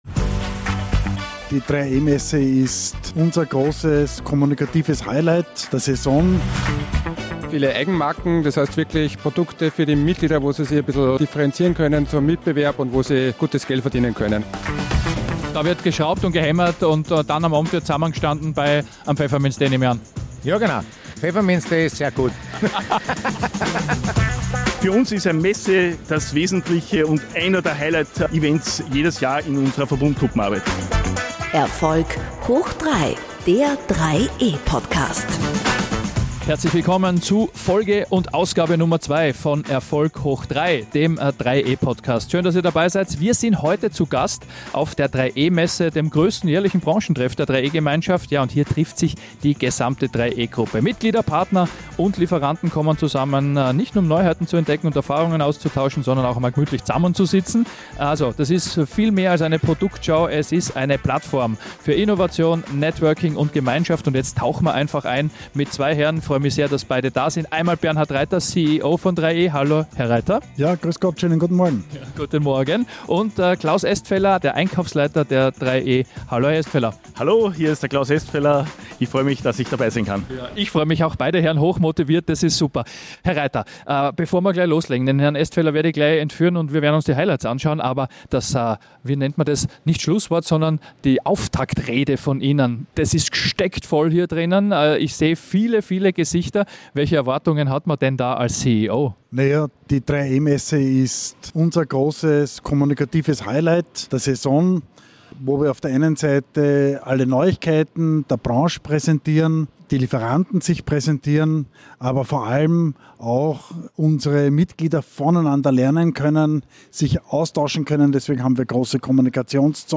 FOLGE 02 - Erfolg hoch 3: live von der 3e Messe 2025 ~ Erfolg hoch 3 Podcast
Beschreibung vor 5 Monaten Direkt vom größten Verbundgruppenevent des Jahres meldet sich diese Ausgabe von "Erfolg hoch 3" mit Stimmen, Eindrücken und Einschätzungen aus erster Hand. Mitglieder und Lieferanten erzählen, was die Messe bewegt, welche Trends sichtbar werden – und warum dieser Tag für die gesamte 3e-Community so wichtig ist.